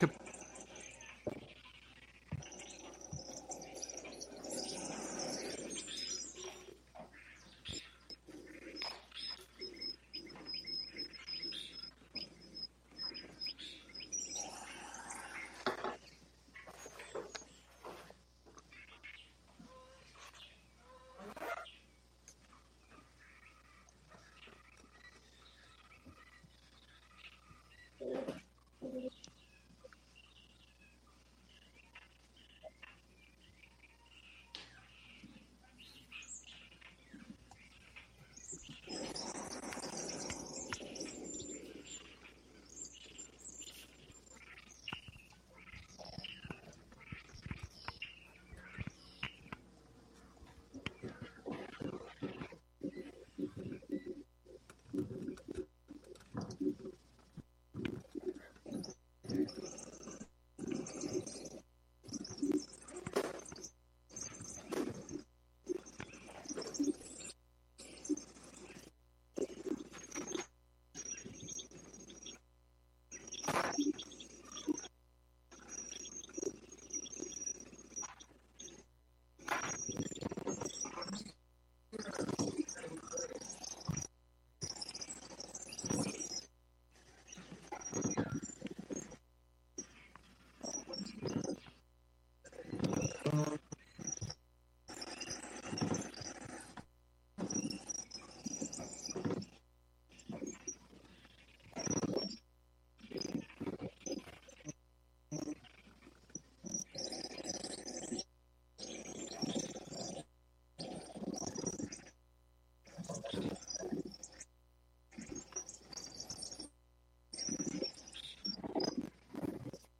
Live from Greene County. The Conduction Series is a collaborative live radio broadcast produced by sound and transmission artists across the continents on Wave Farm’s WGXC 90.7-FM Radio for Open Ears in New York’s Upper Hudson Valley.
Emphasizing LIVE interactivity and media archaeological methods, the series explores themes of migration, feedback, user participation, low-key and on-site interaction with mobile devices, and remote collaboration at scale. This week the group convenes in Greene County, in-person, for the first time together for a series of live broadcasts and experiments.